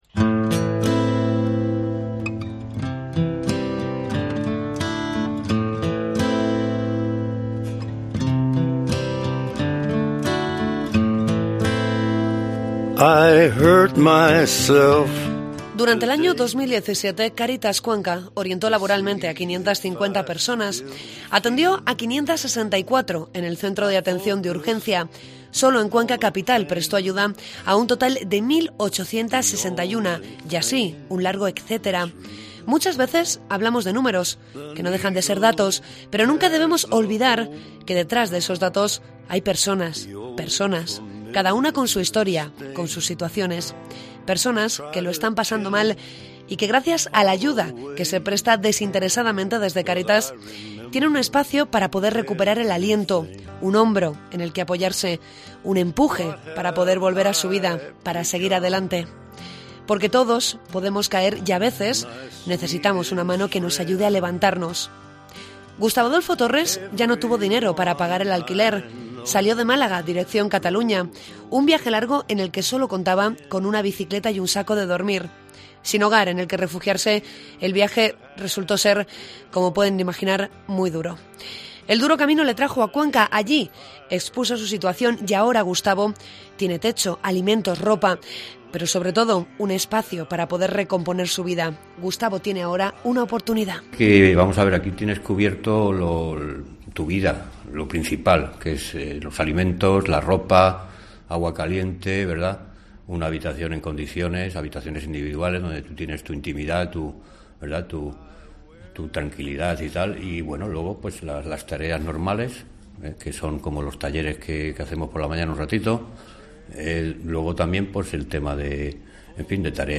Contamos la labor de Cáritas Cuenca a través de las voces de las personas que reciben apoyo y de los voluntarios.